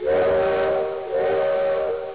TRAIN-WH.WAV